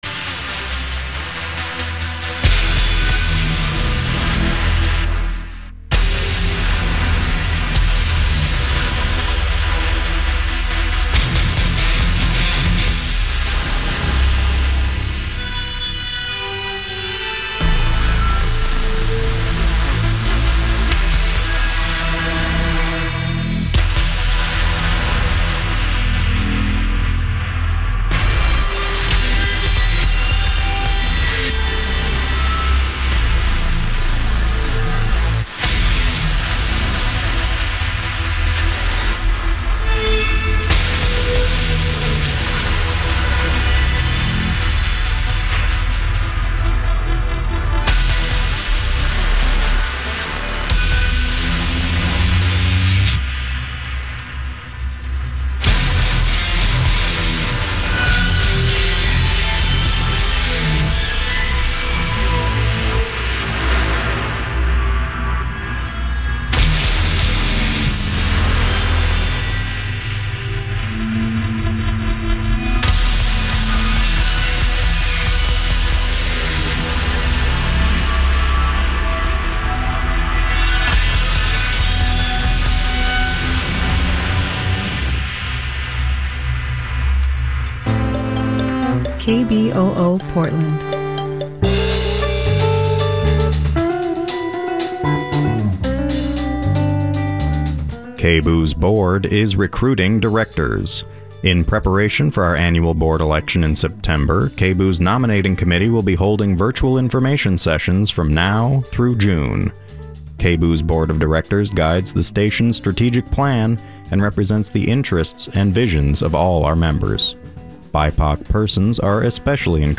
Local poets of color read and discuss their works.